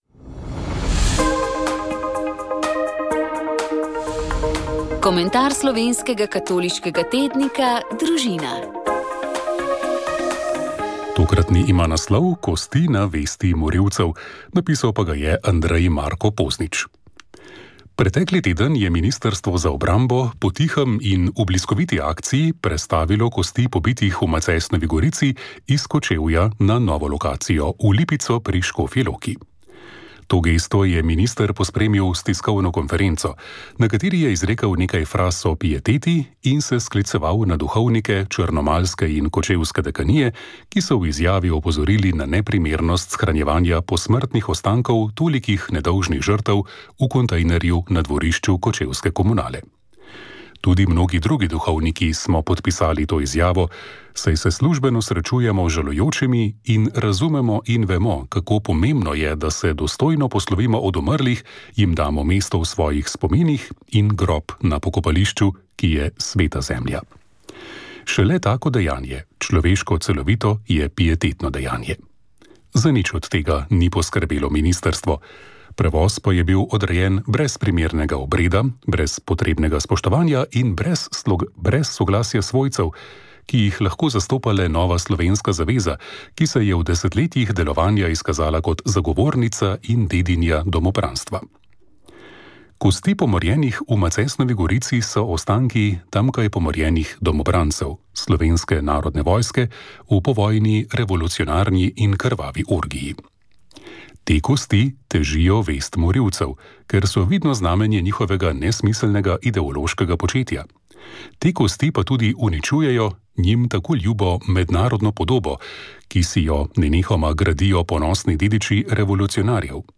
Na kulturni praznik smo v Botaničnem vrtu Univerze v Ljubljani lahko spoznali knjižna dela o bogatem svetu hortikulture pri nas. Rastlinsko bogastvo Slovenije je neizmerno in v primerjavi z bistveno večjimi državami jih Slovenija močno prekaša ali pa ima vsaj enako število rastlin.
Slednja je v pogovoru predstavila še nekaj ostalih knjižnih del.